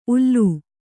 ♪ ullū